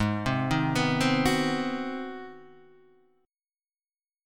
Ab7#9 chord